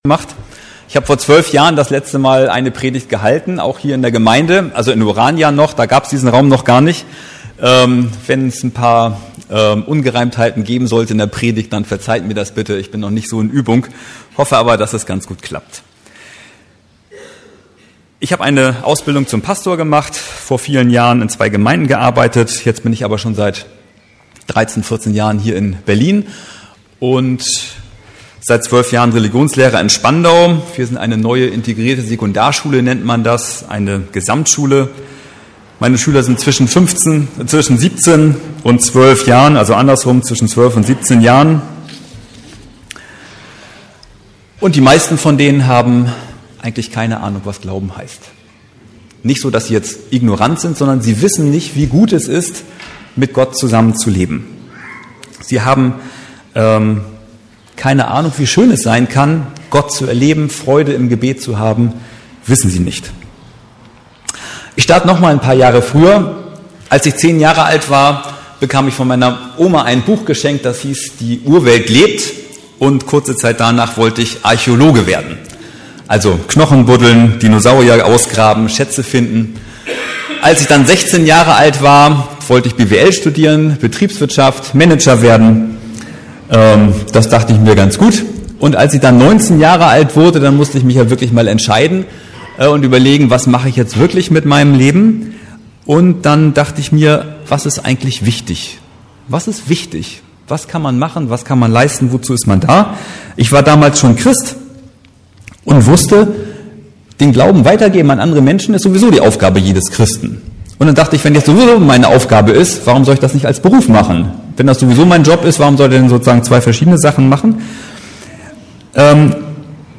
Wir suchen die Hilfe von Gott! ~ Predigten der LUKAS GEMEINDE Podcast